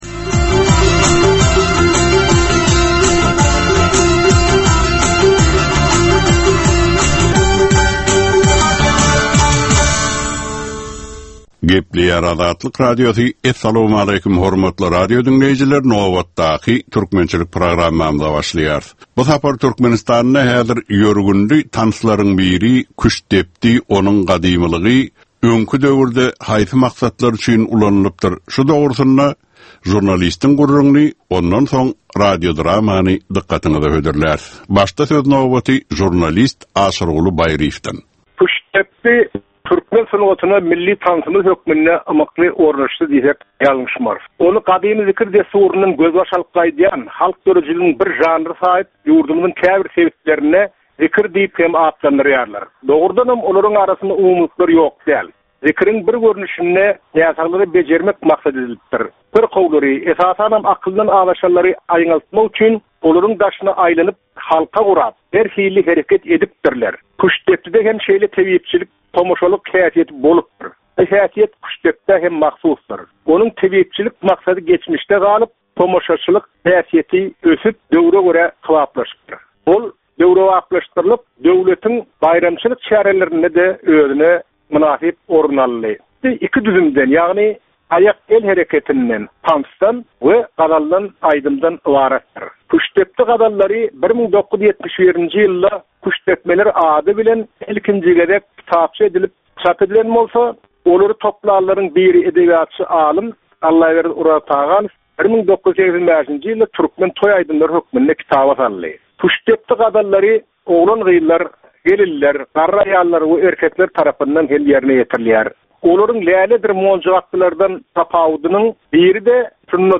Türkmen halkynyň däp-dessurlary we olaryň dürli meseleleri barada 10 minutlyk ýörite gepleşik. Bu programmanyň dowamynda türkmen jemgyýetiniň şu günki meseleleri barada taýýarlanylan radio-dramalar hem efire berilýär.